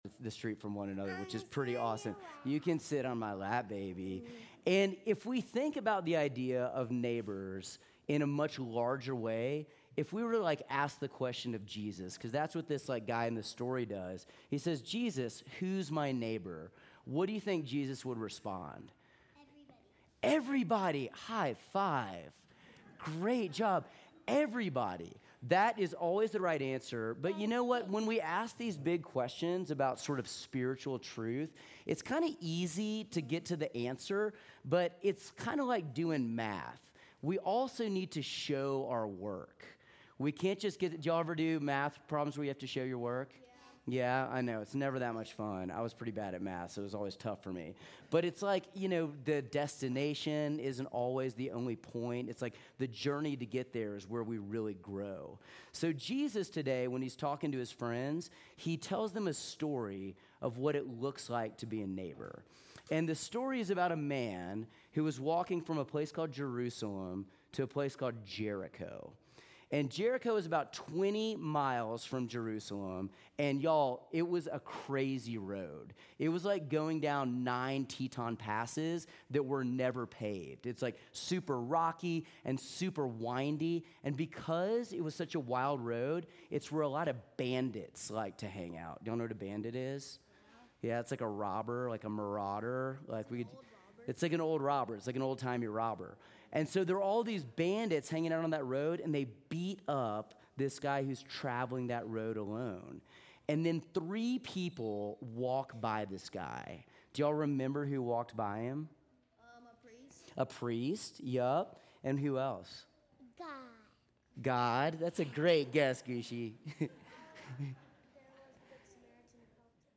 Sermons
St. John's Episcopal Church